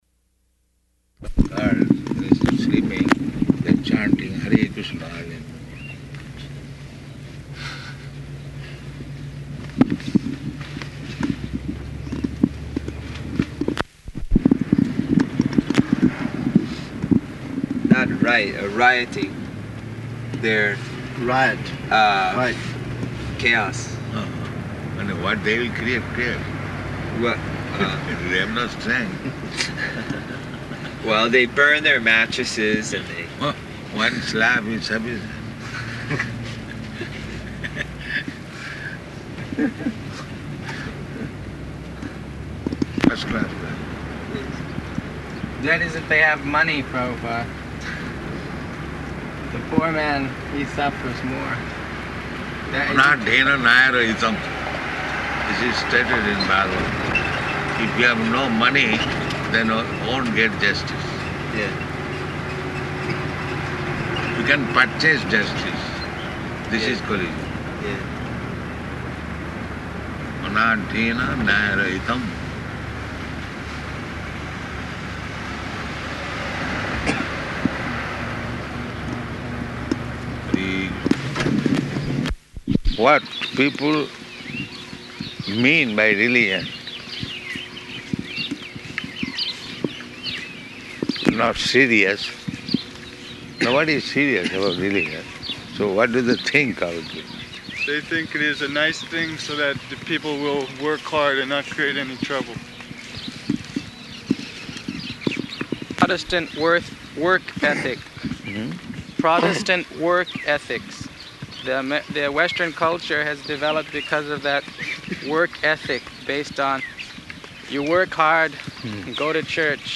Morning Walk
Type: Walk
Location: Honolulu